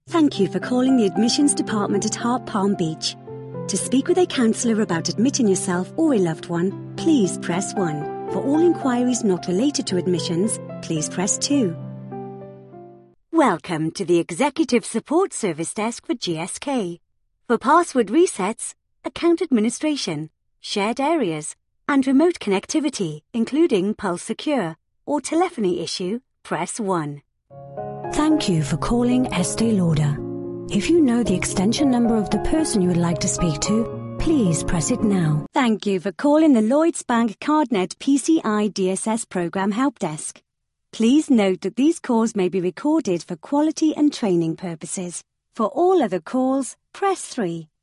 I deliver the perfect blend of British Neutral tones with a splash of smoothness; oodles of charm, a pinch of the natural and believable and you have what my client's consistently say is "The Real Deal".
british english
phone message